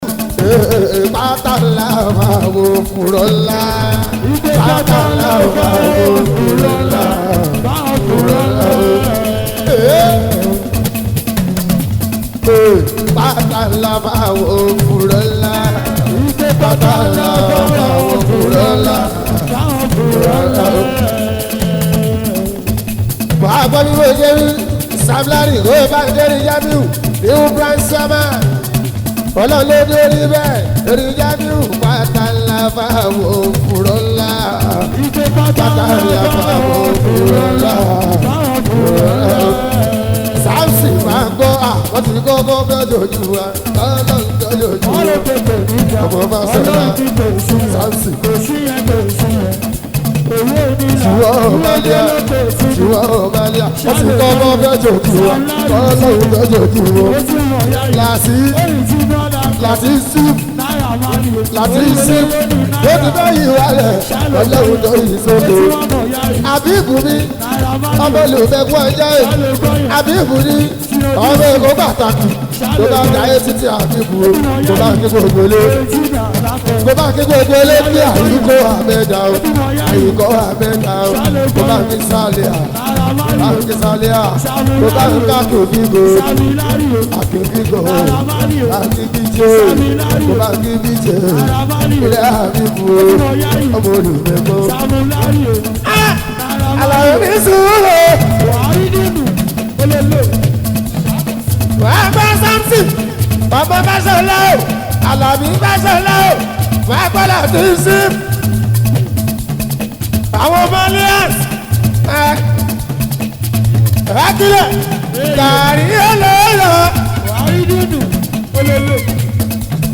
Yoruba Fuji song
Fuji song